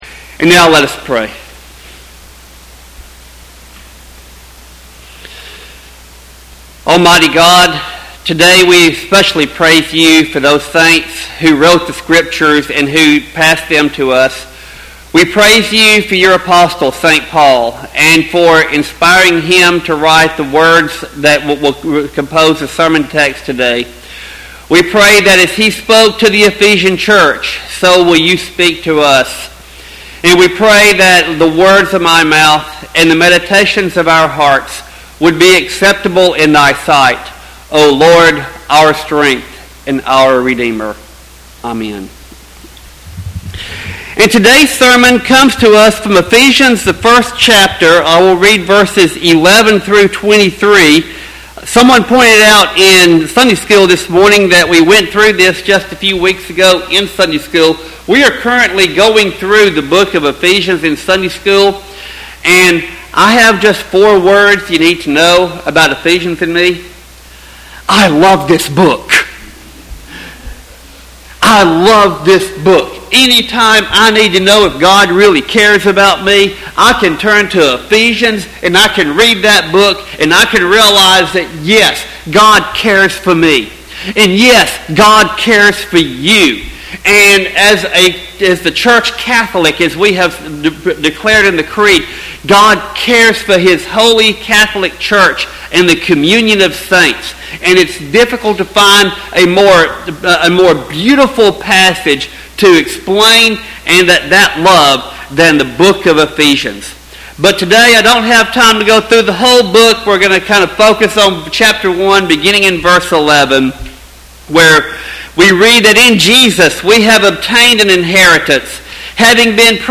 Sermon text: Ephesians 1:11-23.